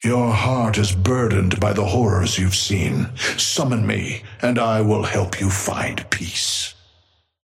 Patron_male_ally_orion_start_02.mp3